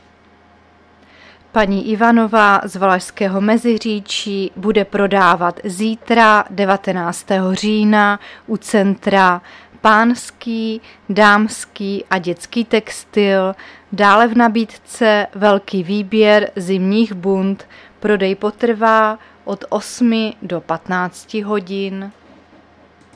Záznam hlášení místního rozhlasu 18.10.2023
Zařazení: Rozhlas